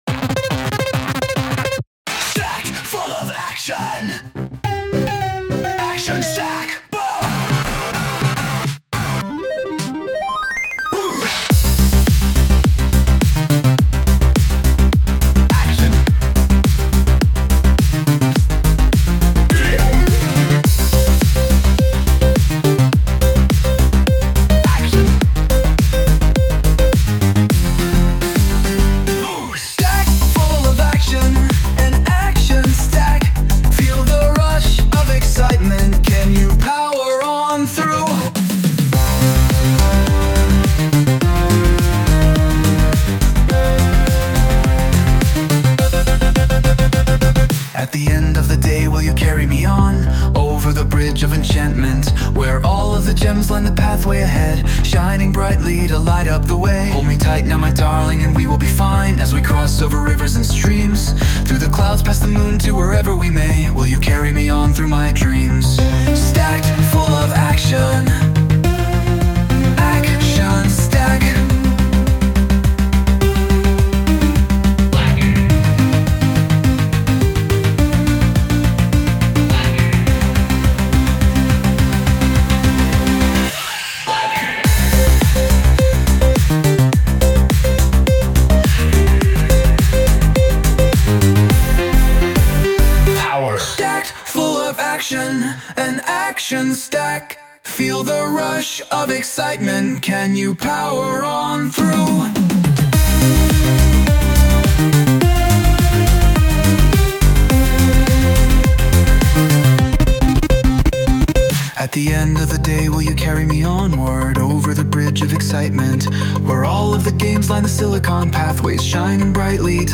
Lyrics : By me
Sound Imported : Walking Yard
Sung by Suno